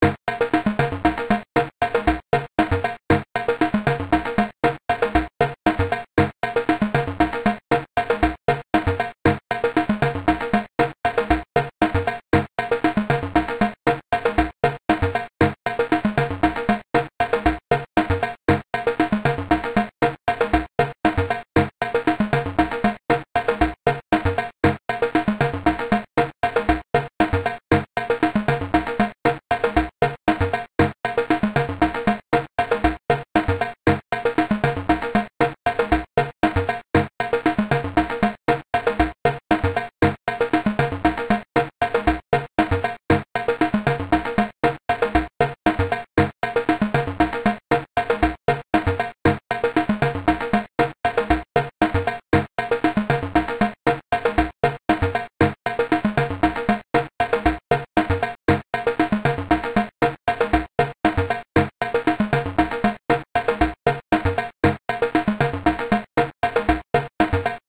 BGM
Speed 150%